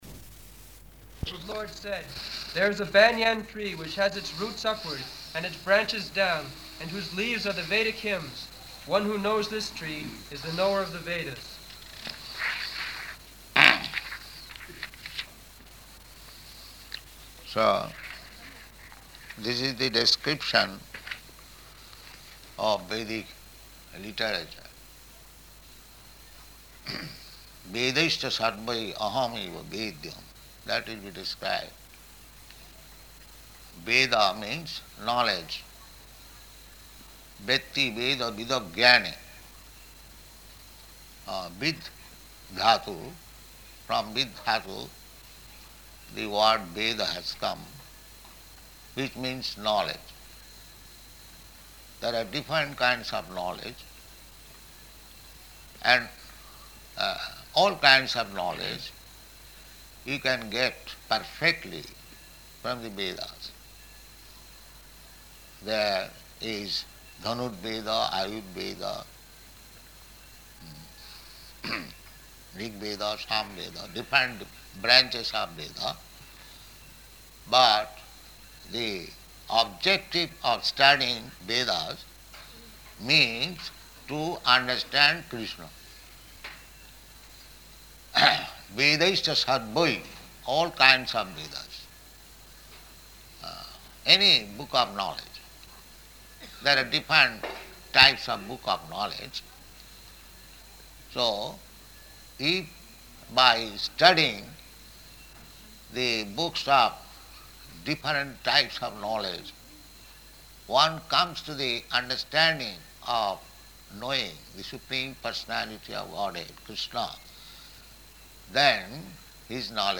Type: Bhagavad-gita
Location: Vṛndāvana